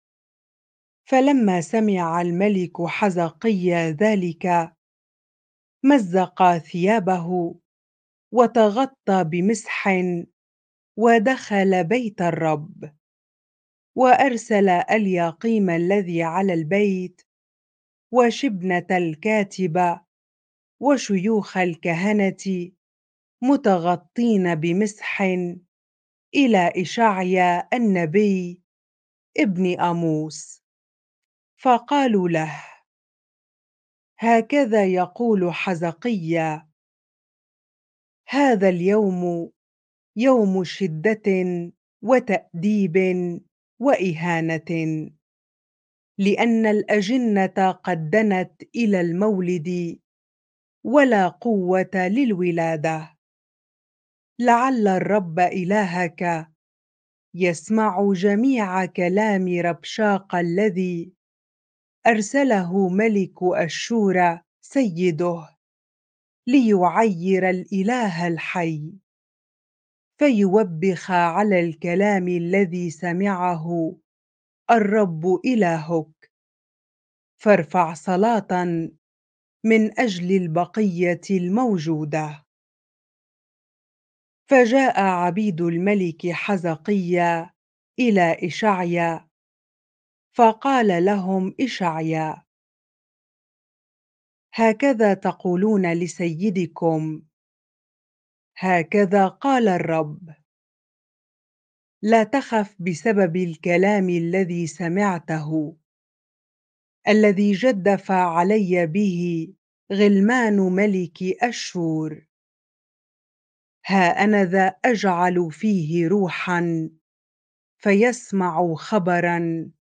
bible-reading-2 Kings 19 ar